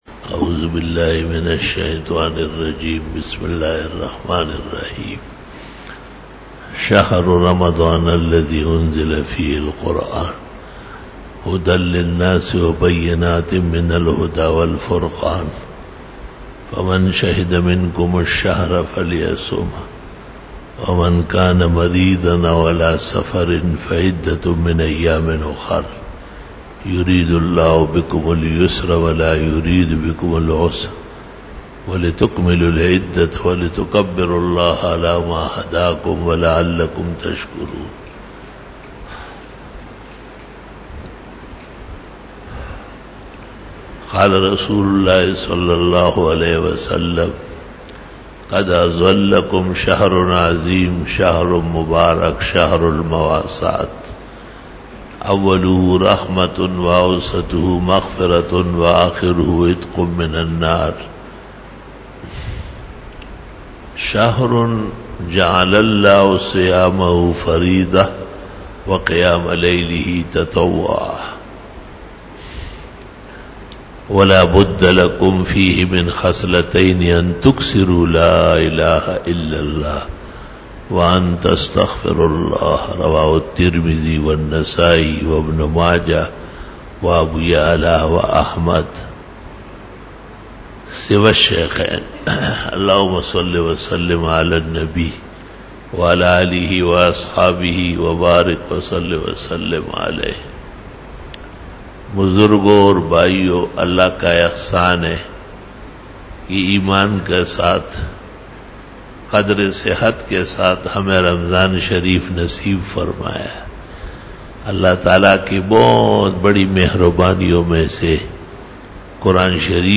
19 BAYAN E JUMA TUL MUBARAK (10 May 2019) (04 Ramadan 1440H)